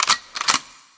Перезарядка автомата звук для эдита